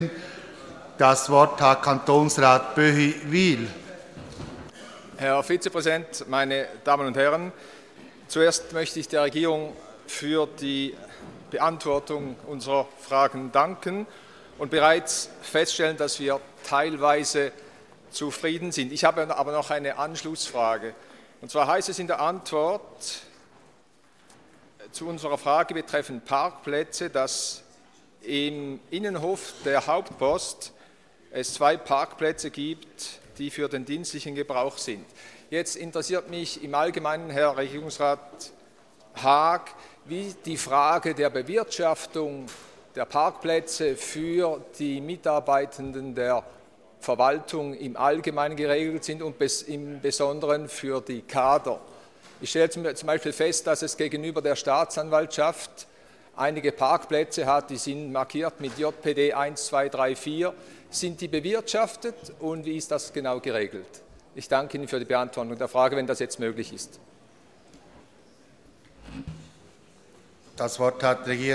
18.9.2013Wortmeldung
Session des Kantonsrates vom 16. bis 18. September 2013